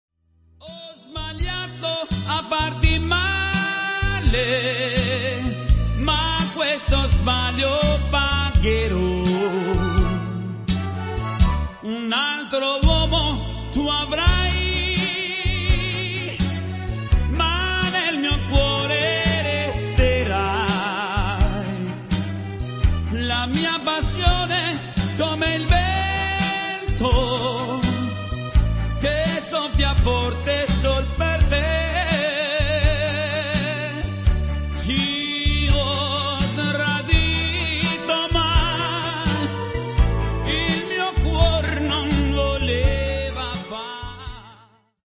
terzinato